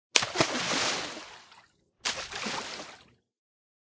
splash2.ogg